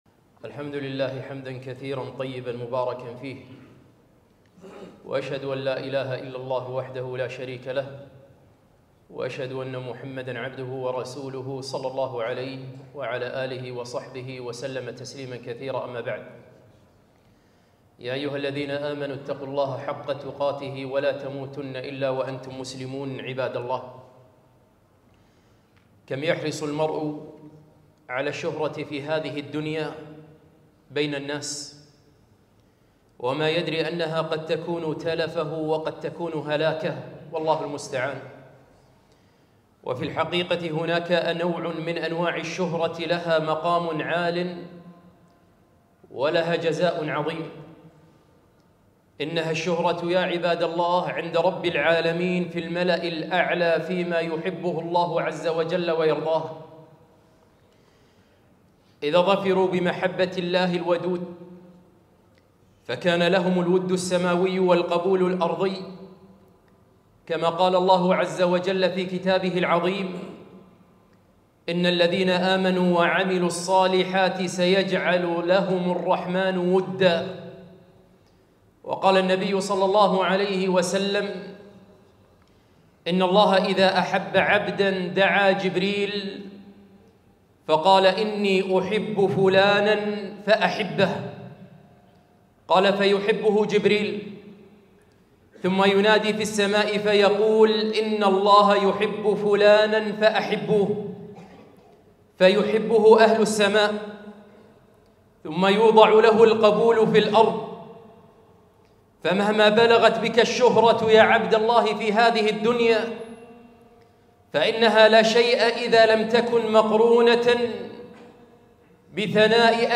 خطبة - كـن مشهـوراً - دروس الكويت